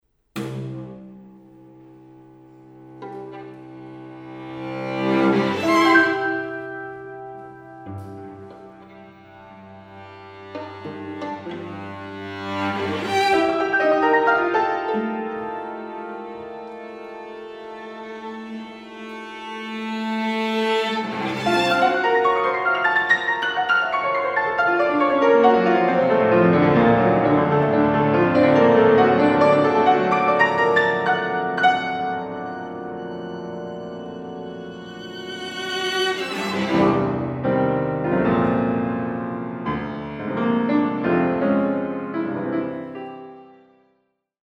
I. Maestoso
piano
violin
cello